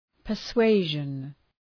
{pər’sweıʒən}